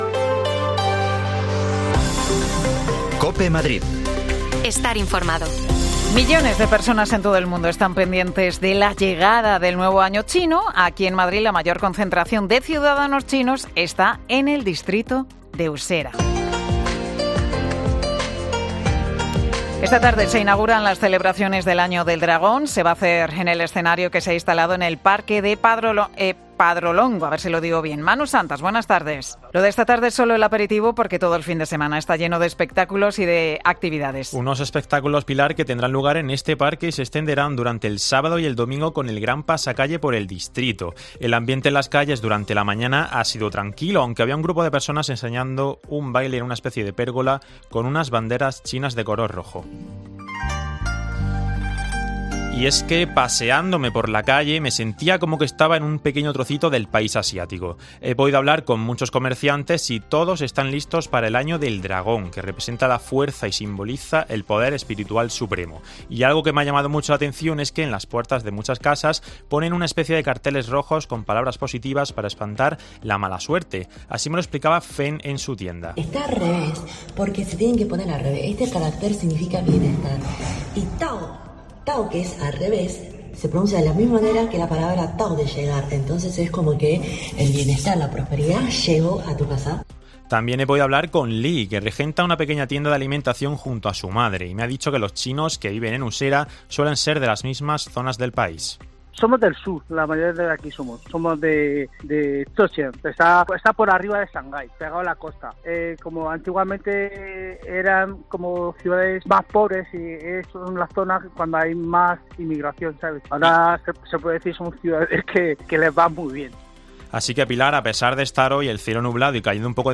En COPE, hemos estado en Usera para hablar con diferentes comerciantes y trabajadores del barrio que nos han contado cuál es la tradición.